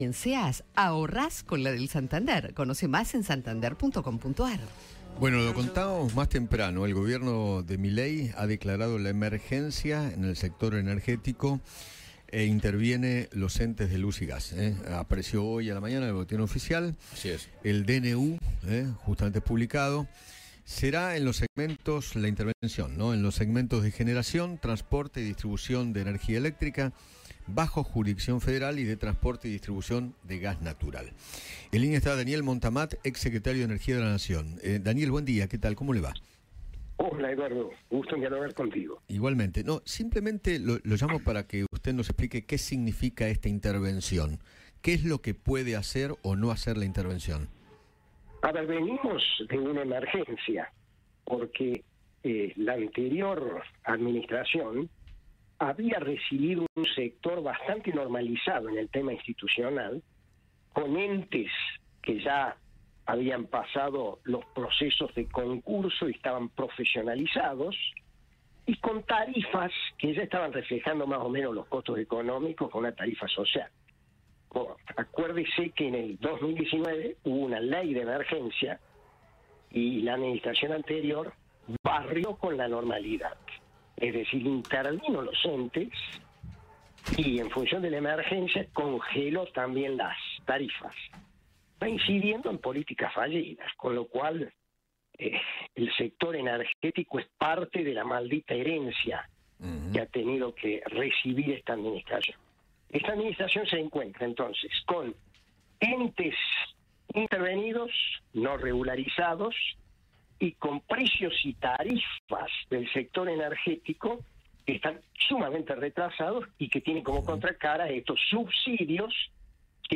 Daniel Montamat, ex secretario de Energía de Nación, dialogó con Eduardo Feinmann sobre el decreto que publicó el gobierno de Milei para declarar la emergencia energética.